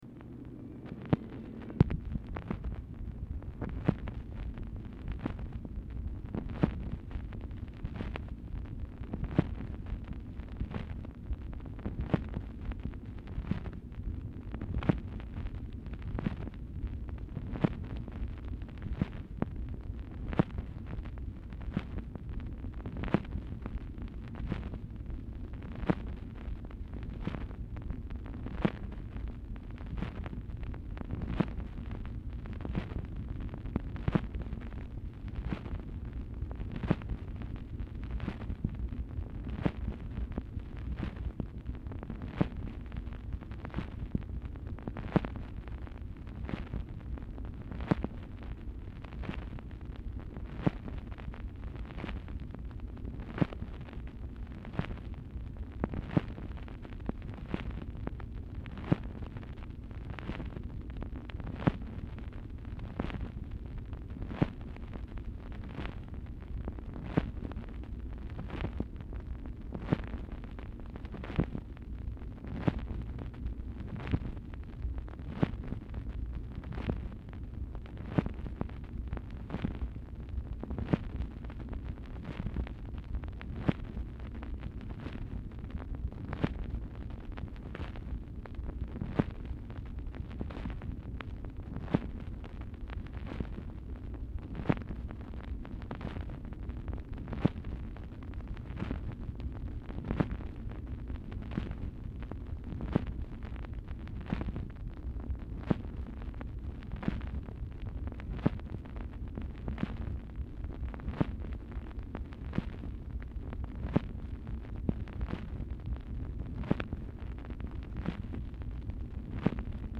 MACHINE NOISE
Format Dictation belt
Specific Item Type Telephone conversation